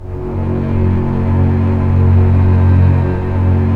Index of /90_sSampleCDs/Roland L-CD702/VOL-1/STR_Cbs Arco/STR_Cbs2 Orchest